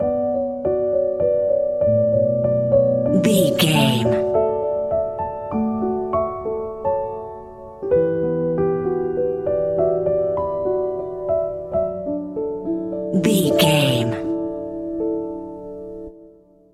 A short and elegant piano phrase
Ionian/Major
piano
contemplative
dreamy
meditative
tranquil
quiet
haunting
melancholy
ethereal
reflective